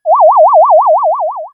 UFO08.wav